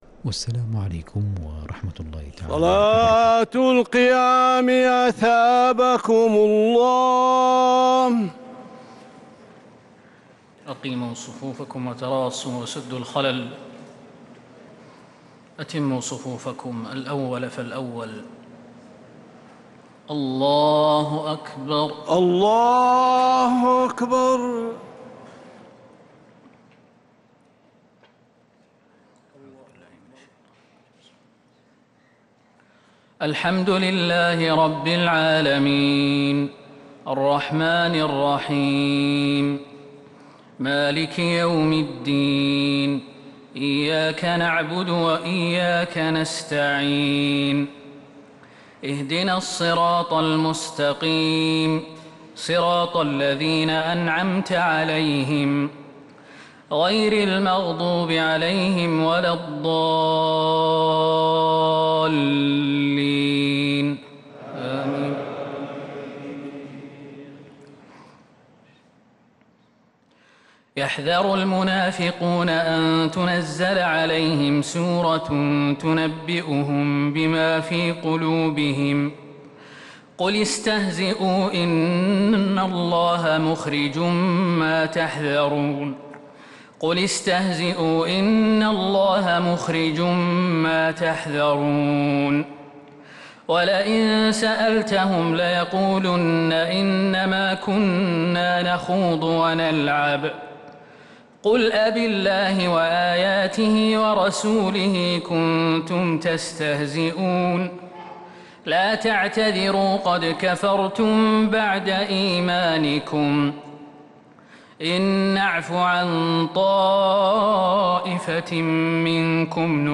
صلاة التراويح ليلة 14 رمضان 1443 للقارئ خالد المهنا - الثلاث التسليمات الأولى صلاة التراويح